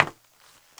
METAL 2C.WAV